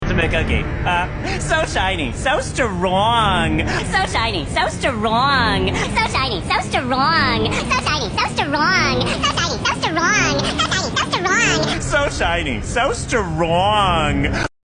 so shiny so strong Meme Sound Effect
Category: Games Soundboard